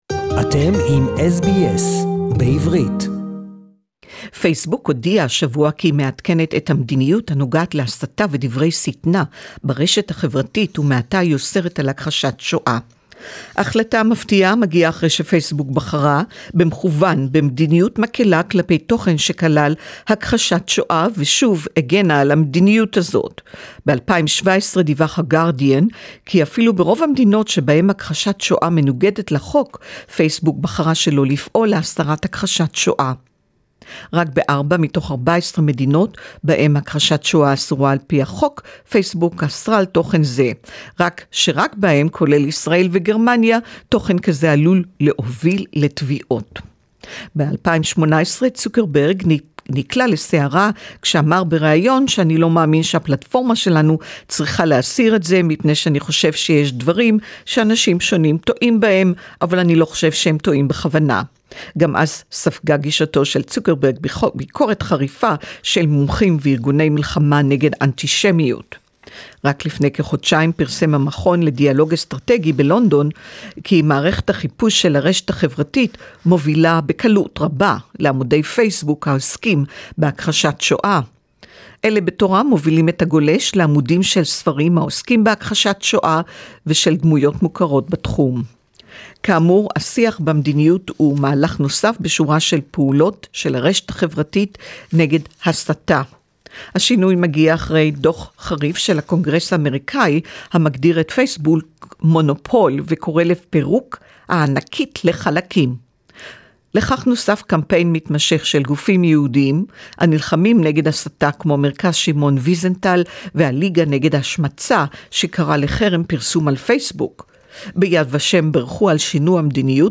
Facebook is banning Holocaust denial and distortion of facts posts on their social media platforms Hebrew Report.